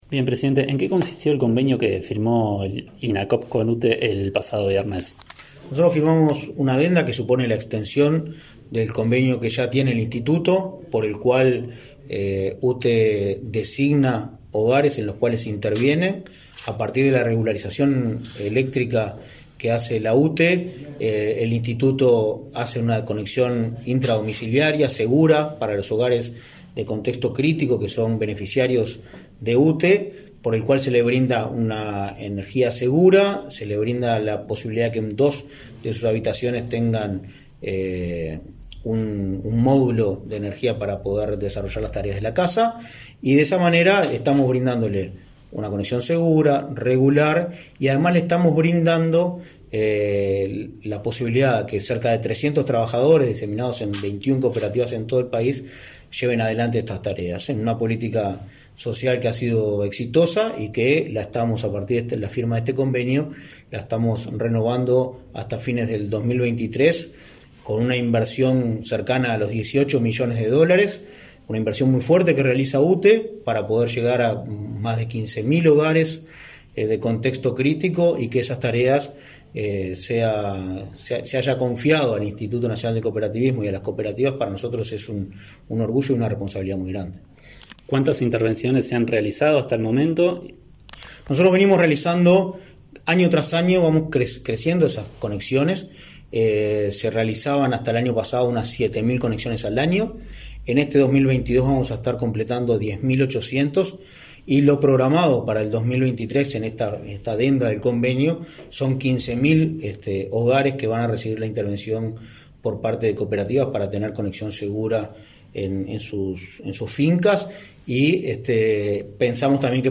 Entrevista al presidente del Inacoop, Martín Fernández